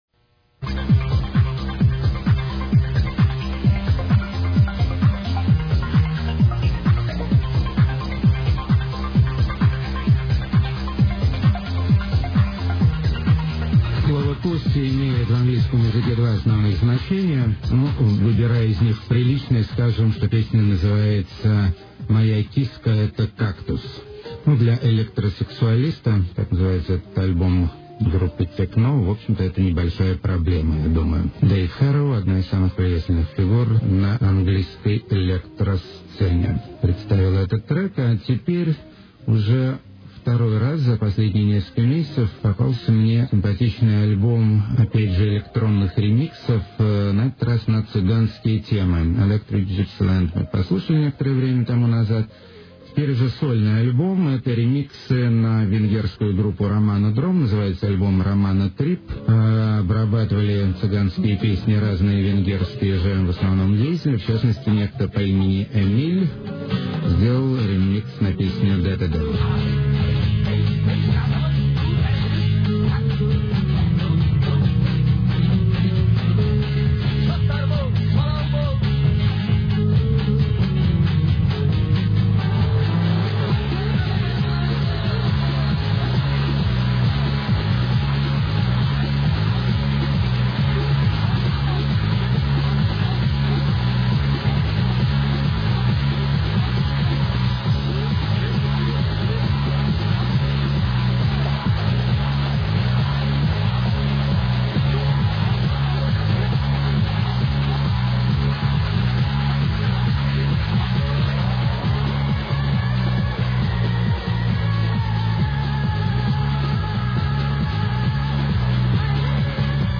laptop meets exotica
funny swinging hiphop ragga
psychofolk with a sense of humour
lo-fi electronic vocal something
futuristic lounge vignettes